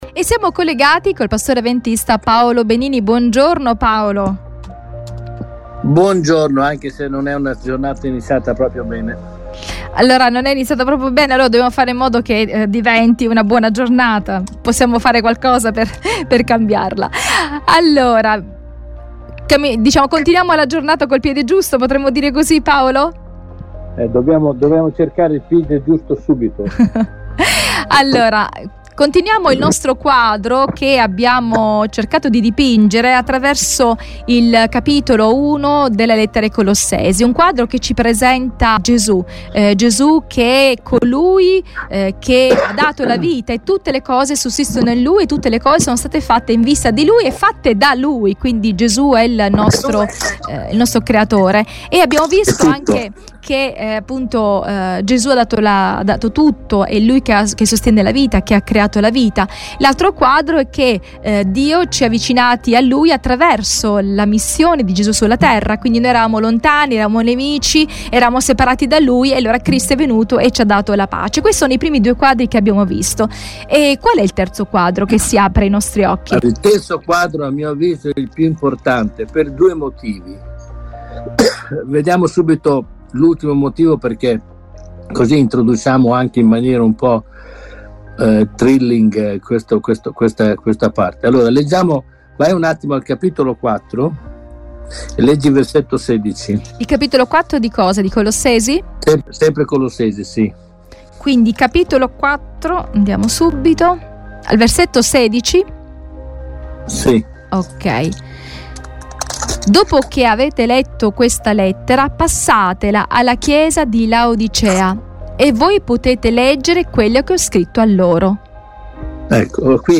Ne abbiamo parlato con il pastore avventista